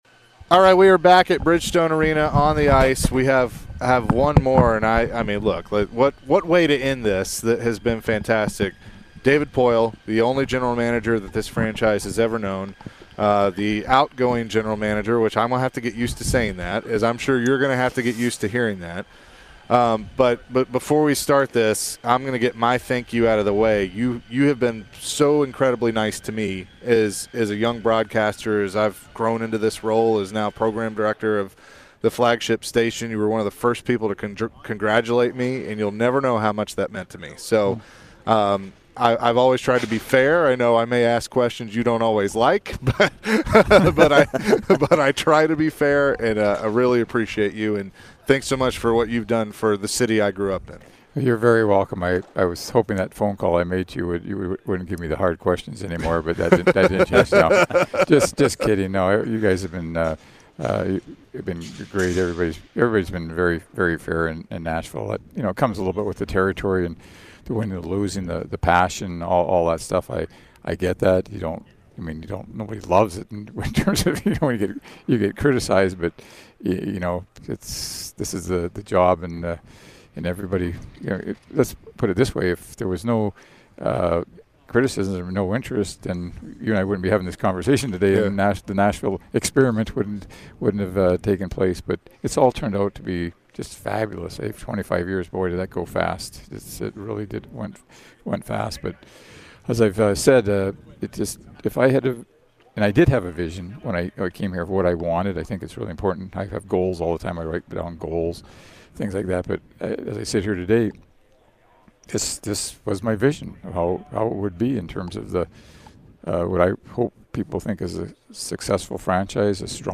David Poile Interview (2-27-23)